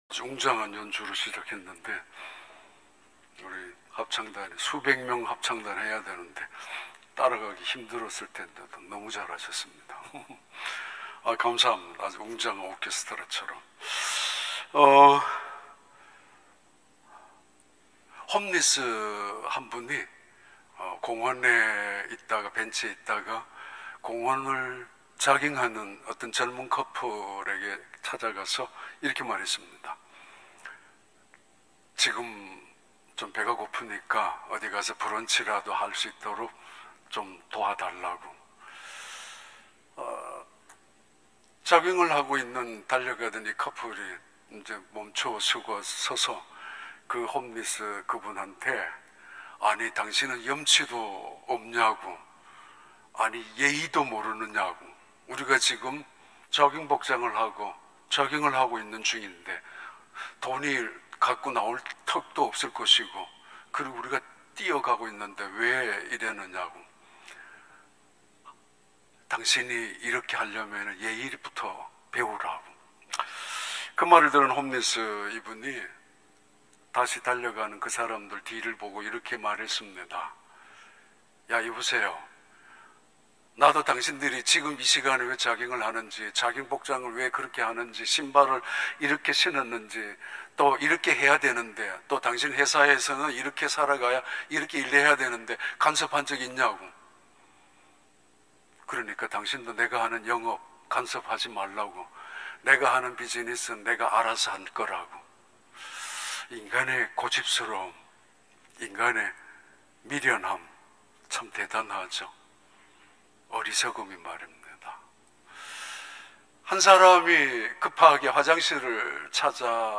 2022년 3월 20일 주일 3부 예배